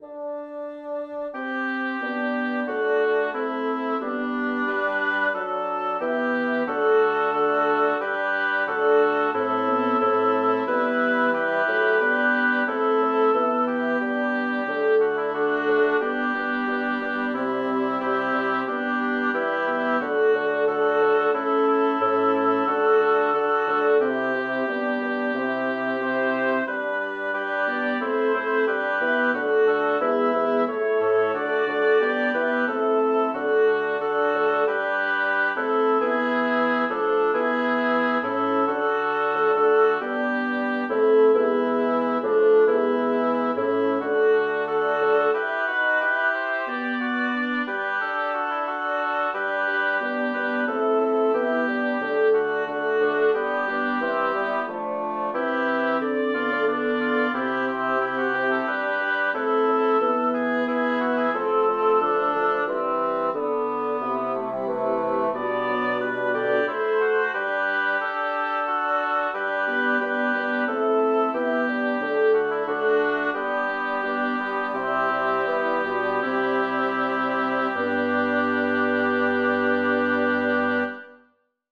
Title: Am Abend spat beim kühlen Wein Composer: Orlando di Lasso Lyricist: Number of voices: 5vv Voicing: SATTB Genre: Secular, Lied
Language: German Instruments: A cappella